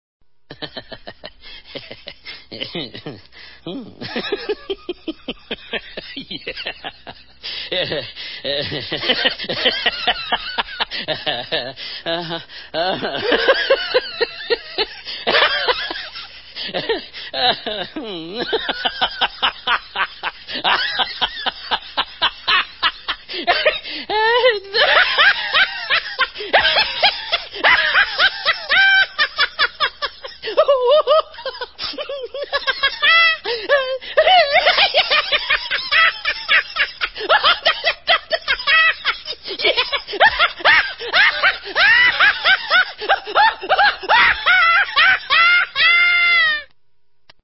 Risada Hilária Homem 47 Segundos
Risada de um homem desconhecido muito hilária, com 47 segundos de áudio.
risada-hilaria-homem-47-segundos.mp3